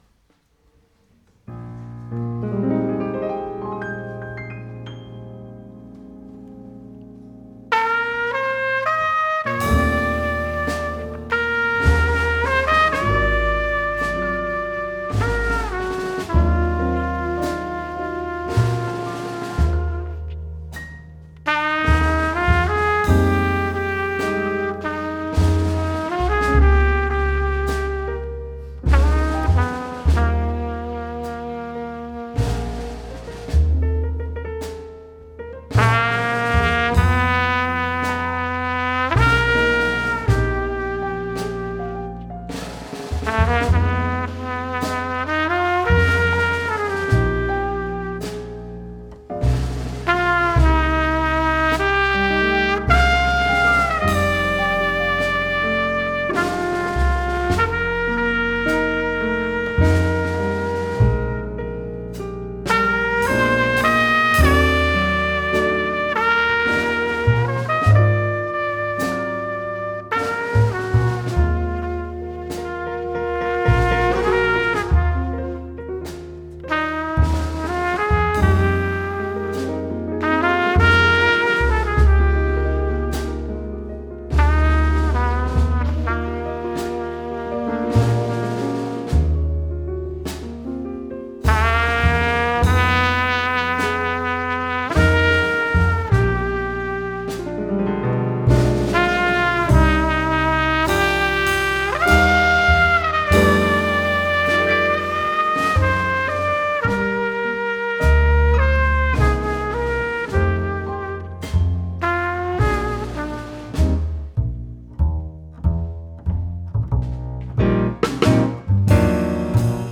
Small Band. Big Sound.